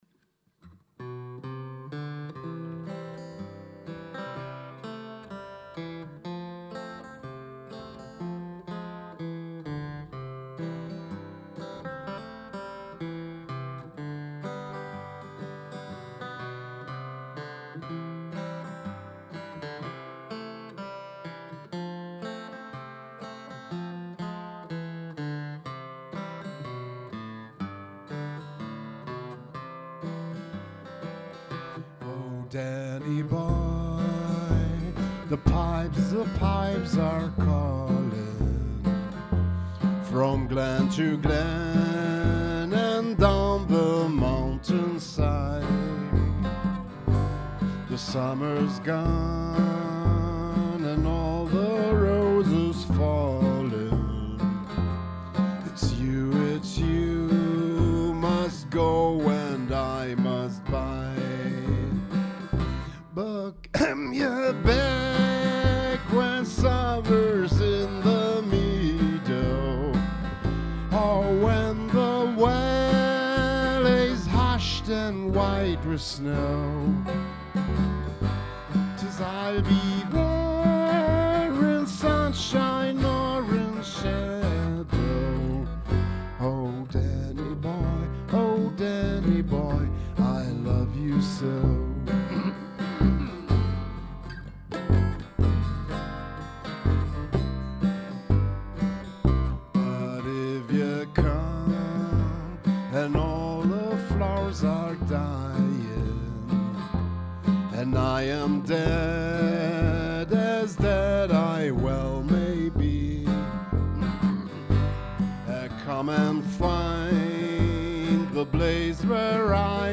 Genre: Folk.